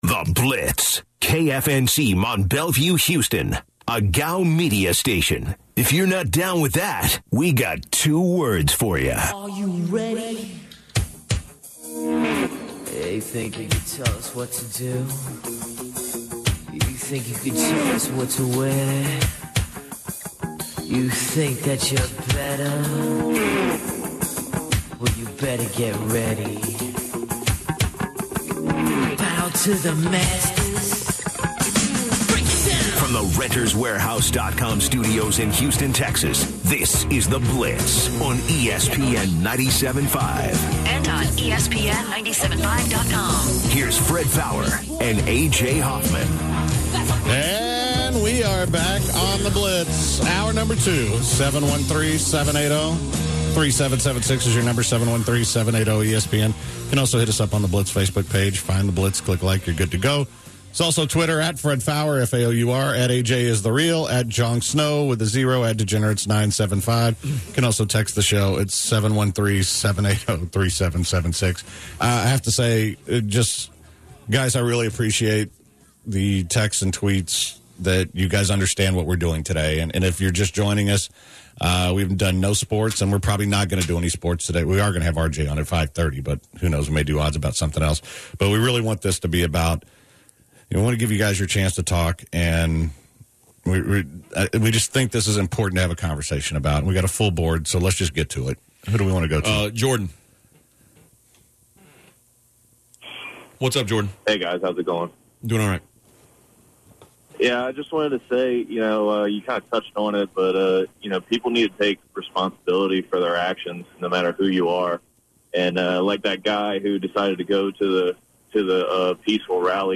discuss with multiple listeners about their views regarding the police, police force, and race.